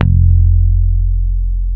-JP PICK F 2.wav